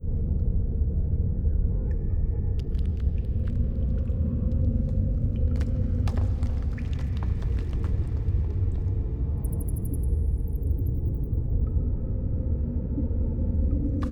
caveAmbiance.wav